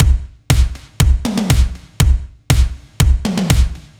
Index of /musicradar/french-house-chillout-samples/120bpm/Beats
FHC_BeatB_120-02_KikSnrTom.wav